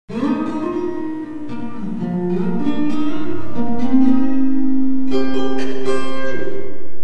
Reverb with 2.5 second reverb time and .7 decay
Reverb 2.5 sec 0.7 decay